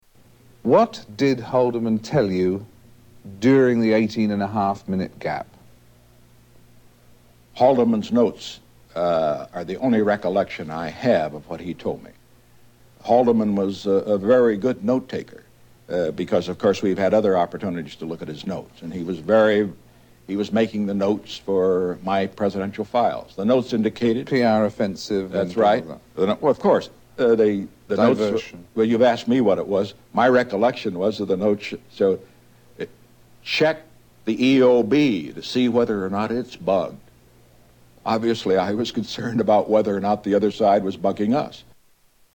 Tags: Historical Frost Nixon Interview Audio David Frost Interviews Richard Nixon Political